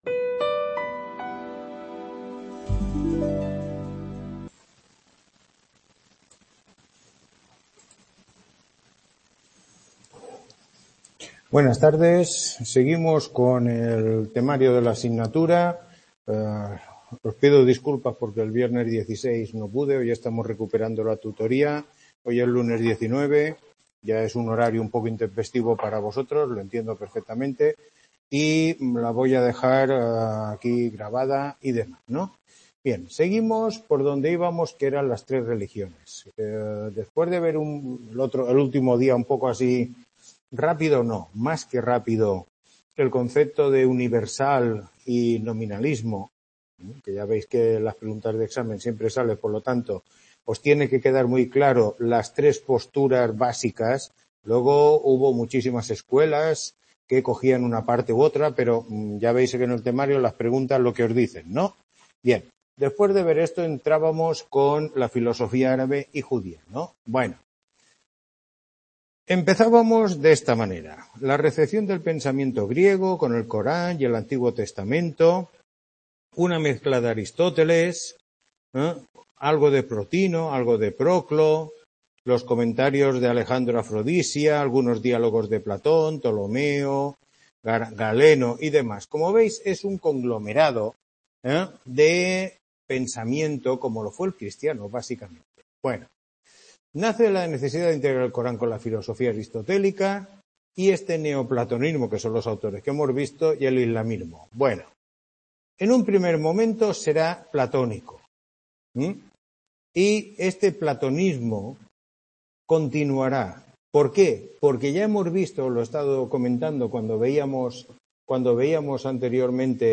Tutoría 10